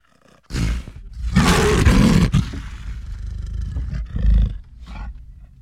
Lion sound - Eğitim Materyalleri - Slaytyerim Slaytlar